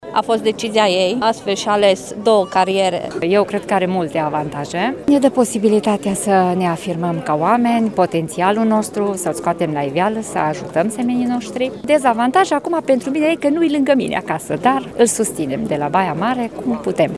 Festivitatea a avut loc în incinta Cetăţii Medievale din Tîrgu Mureş.
Părinții studenților veniți din toate colțurile țării au asistat cu emoție la depunerea jurământului militar:
juramant-parinti.mp3